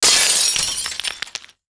GlassSmash MIX64_51_7.wav